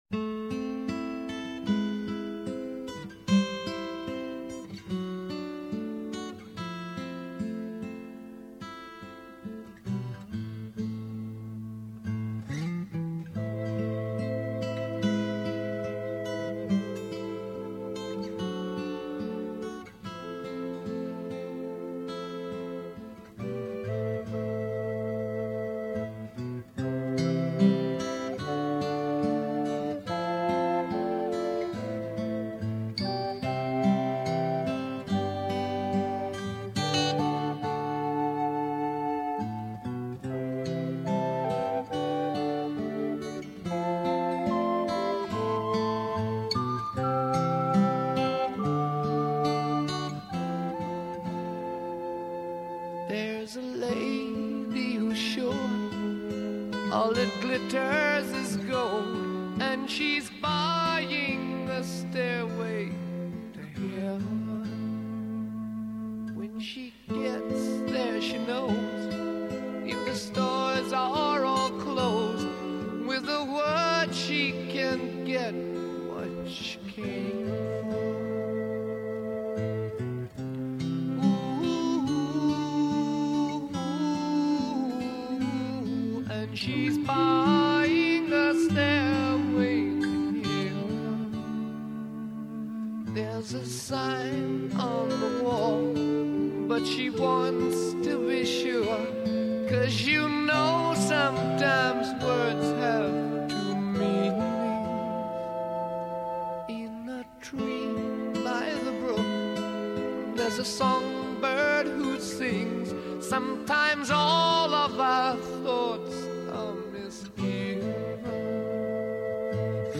تک نوازی گیتار
موسیقی راک